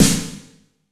AMBIENT S9-R 1.wav